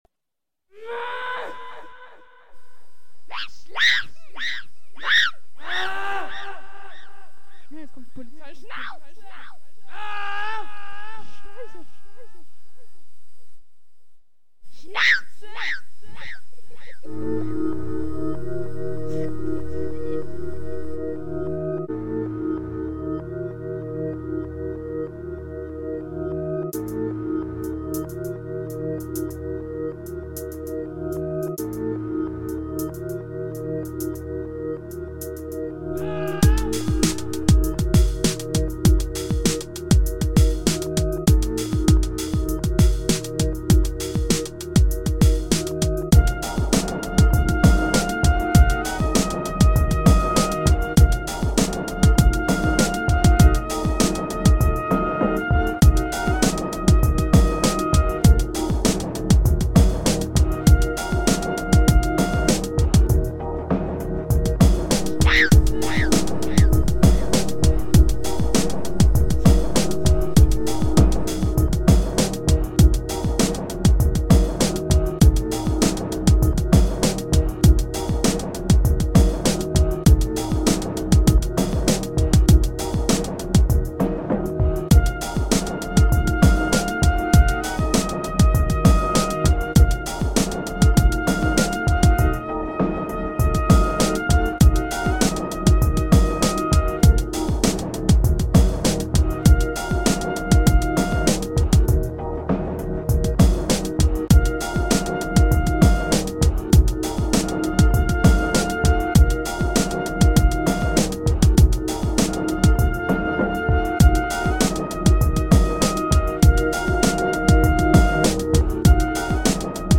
(Achtung, der Anfang ist echt laut, nicht erschrecken xD)
LOL Die Geräusche sind mein Kumpel und ich, als wir mal an einem Freitagabend auf unserem Balkon standen und irgendeiner (im Sommer) rumgeböllert hat Daher dieses schrille "SCHNAUze".